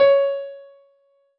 piano-ff-53.wav